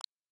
button.m4a